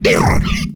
combat / creatures / alien / he / attack3.ogg